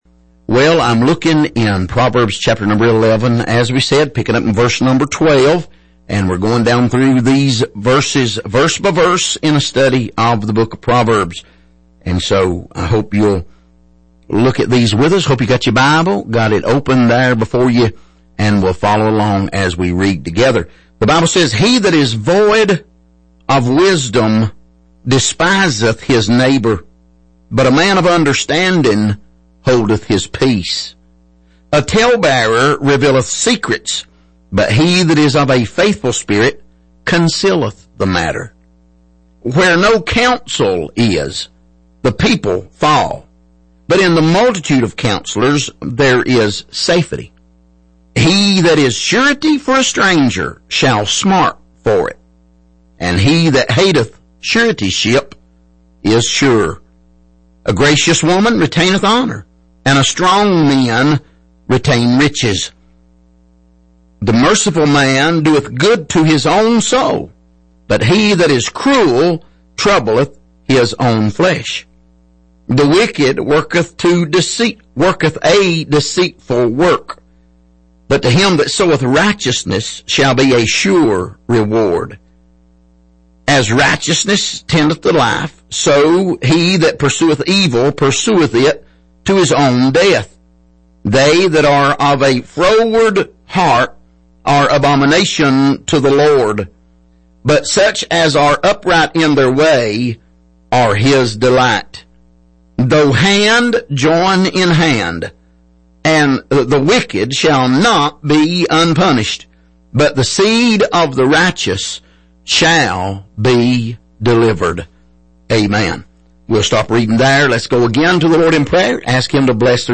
Passage: Proverbs 11:12-21 Service: Sunday Evening